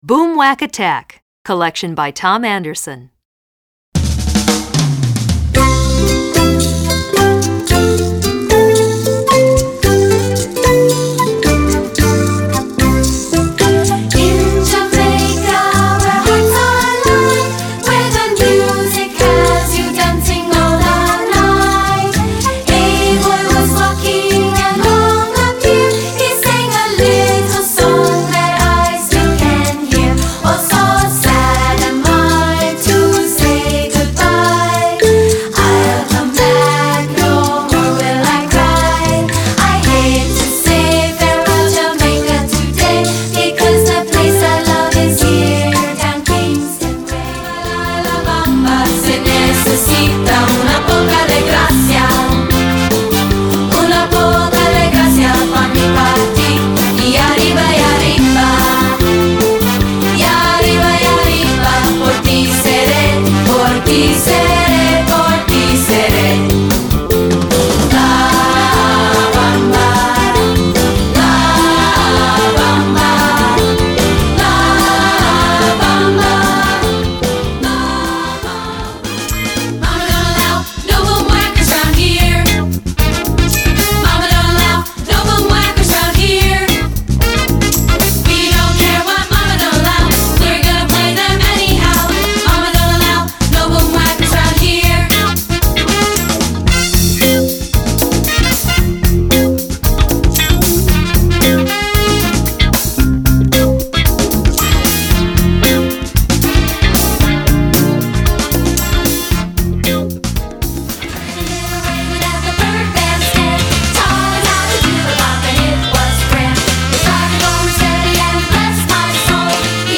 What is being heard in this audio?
Gattung: Boomwhackers Besetzung: Instrumentalnoten für Schlagzeug/Percussion